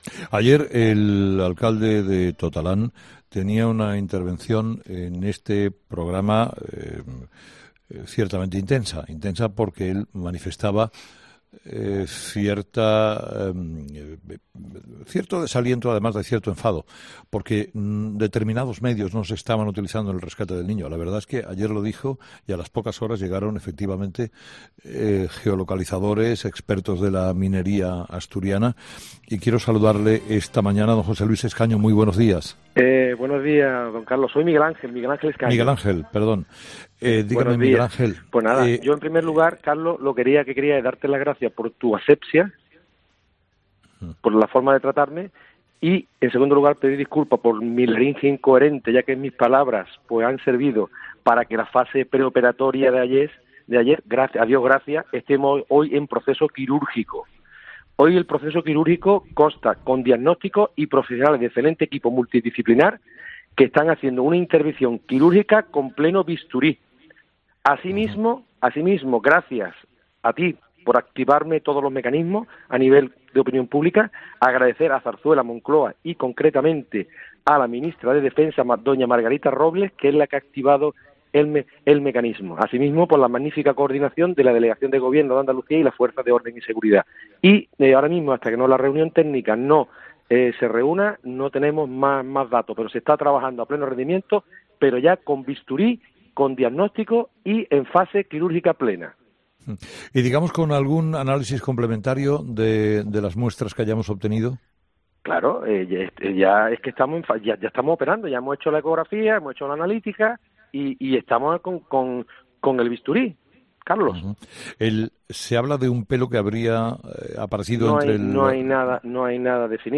Entrevistado: "Miguel Ángel Escaño"